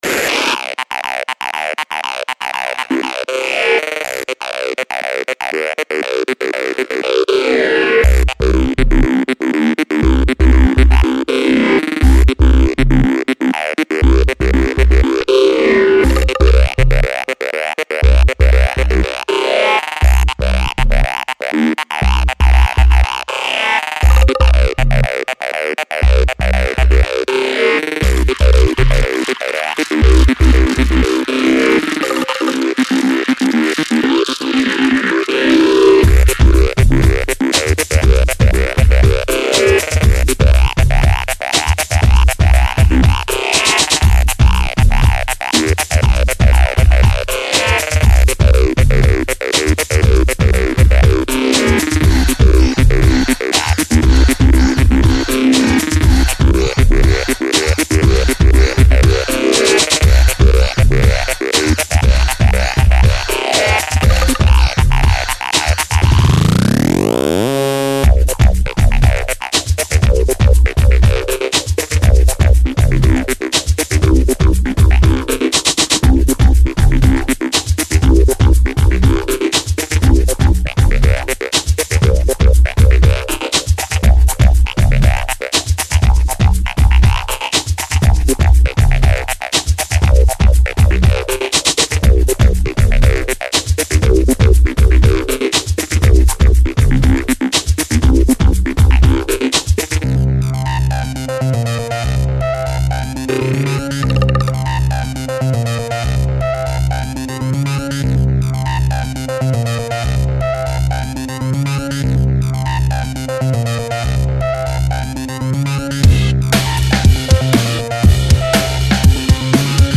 • Жанр: Электронная